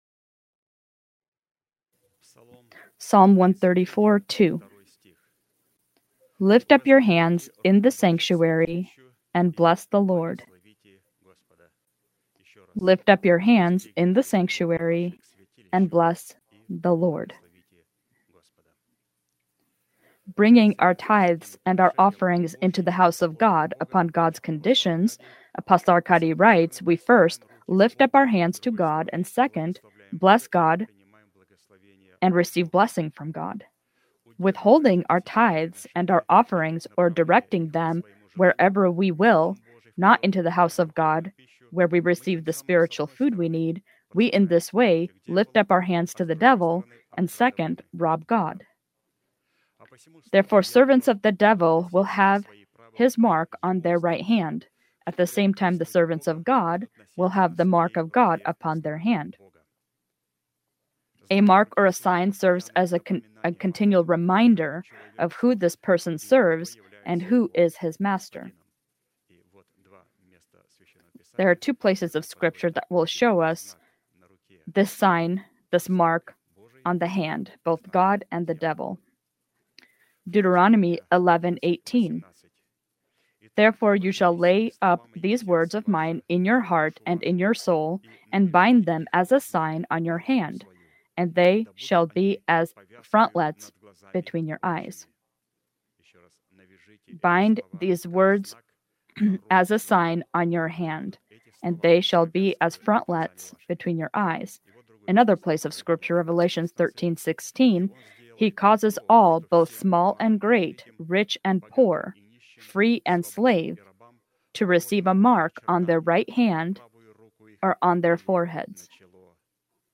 Sermon title: